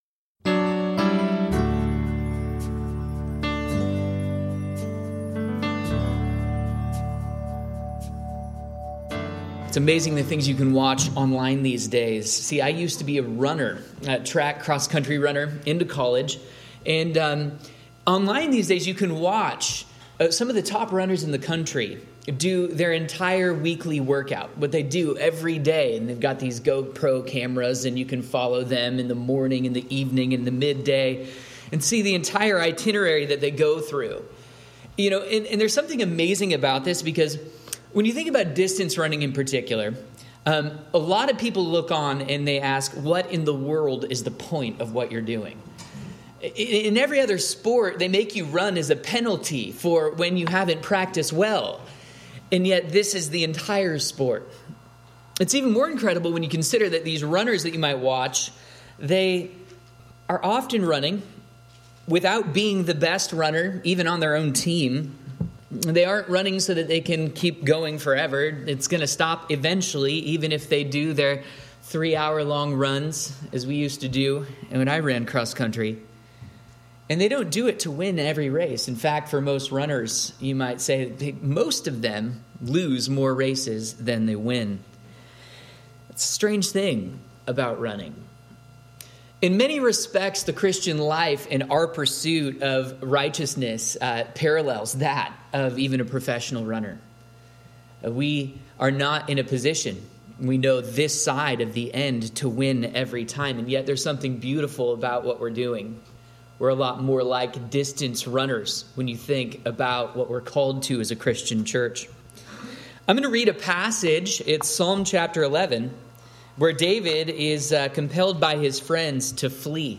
Sermon audio and video are posted.